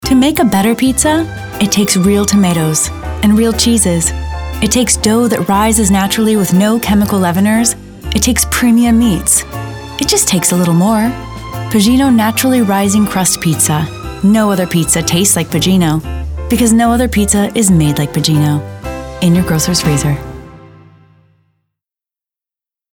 announcer, caring, character, concerned, confident, girl-next-door, inspirational, mature, middle-age, older, retail, romantic, sexy, warm